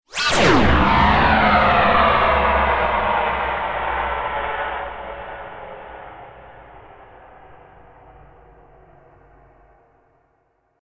Âm thanh Ma Thuật sấm sét Rùng rợn
Thể loại: Âm thanh hung dữ ghê sợ
Với âm thanh sống động và bí ẩn, hiệu ứng này sẽ tạo ra không khí huyền bí và kịch tính cho các dự án video của bạn.
am-thanh-ma-thuat-sam-set-rung-ron-www_tiengdong_com.mp3